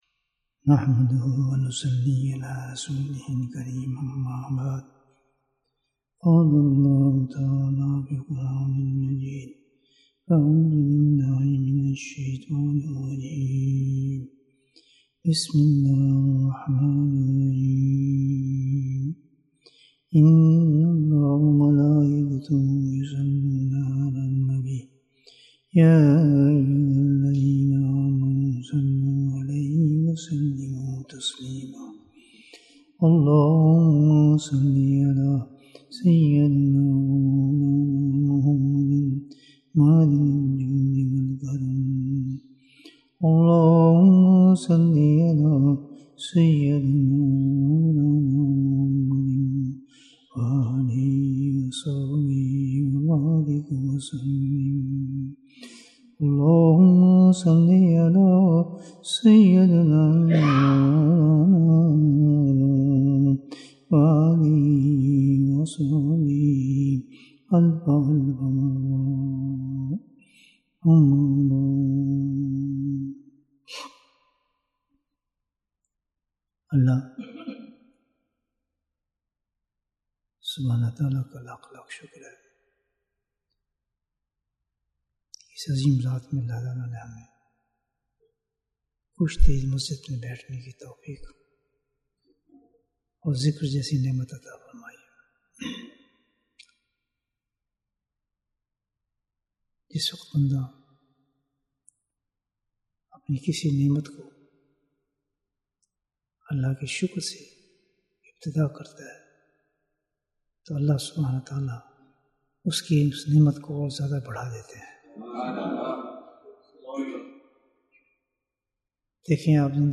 Bayan, 83 minutes 27th October, 2022 Click for English Download Audio Comments Allah hu Akbar Another pearl of a bayan 31st Oct, 2022